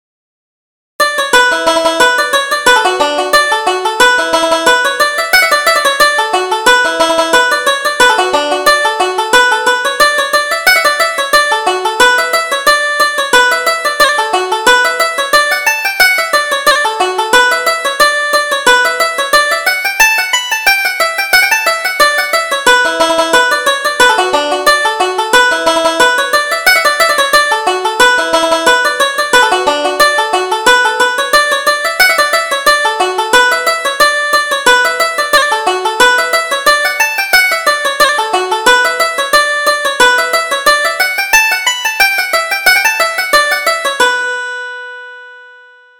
Reel: The Miltown Maid